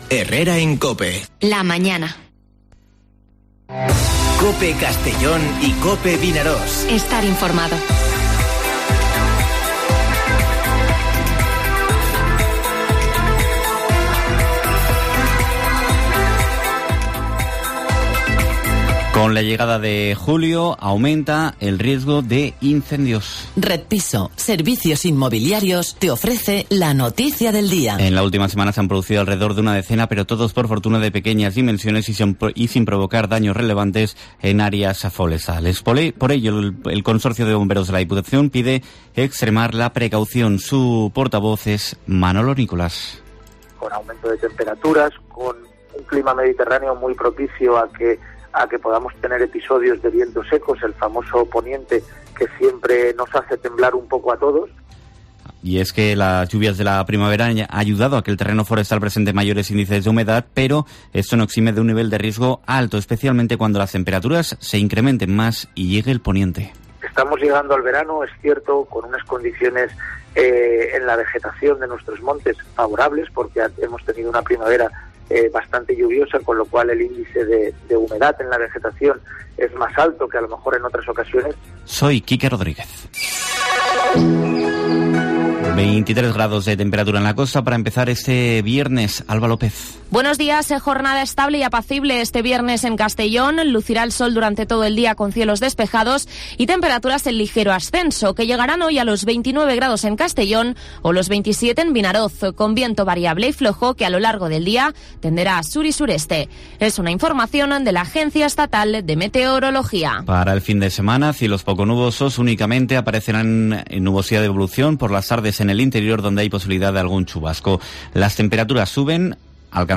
Informativo Herrera en COPE en la provincia de Castellón (02/07/2021)